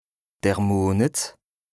Français Dialectes du Bas-Rhin Dialectes du Haut-Rhin Page
2APRESTA_OLCA_LEXIQUE_INDISPENSABLE_BAS_RHIN_169_0.mp3